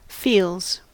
Ääntäminen
Synonyymit fee-fees Ääntäminen : IPA : /fiːlz/ US : IPA : [fiːlz] Haettu sana löytyi näillä lähdekielillä: englanti Käännöksiä ei löytynyt valitulle kohdekielelle.